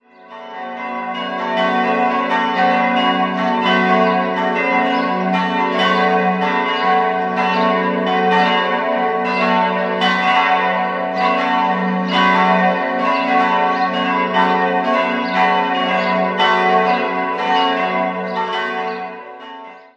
4-stimmiges ausgefülltes Fis-Moll-Geläute: fis'-a'-h'-cis''
fis'+3 668 kg 1.030 mm 1951
Glocke 2 a'+7,5 ca. 400 kg 883 mm 1611
h'+4 228 kg 761 mm 1950
bell